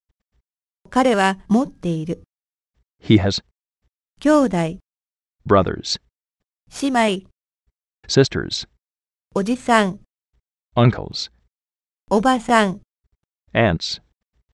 ◇音声は日本語、英語ともに高音質のスピーチエンジンを組み込んだ音声ソフトを使って編集してあります。
音声−語句